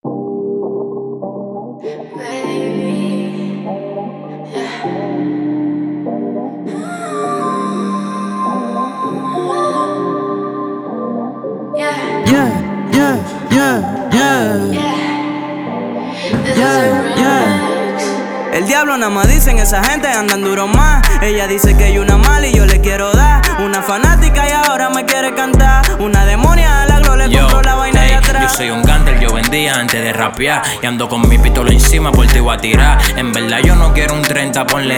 Жанр: R&B / Латино / Соул